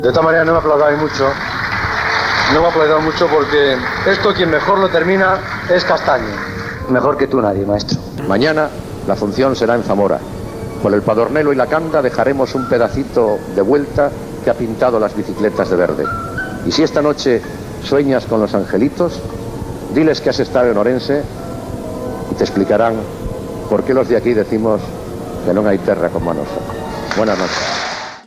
Espai fet cara al públic a Ourense, on ha acabat una etapa de la Vuelta Ciclista a España. Comentari final del programa
Esportiu